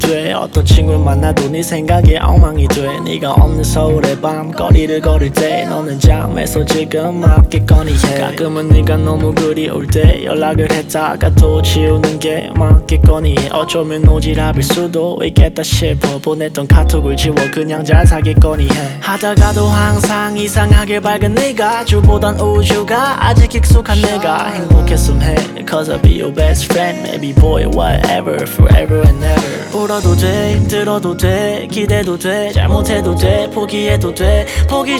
Жанр: Поп музыка / R&B / Соул
K-Pop, Pop, R&B, Soul